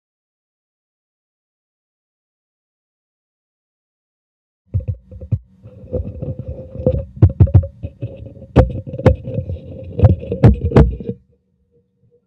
Podczas regulacji pokręteł w trakcie nagrywania pojawiają się słyszalne trzaski.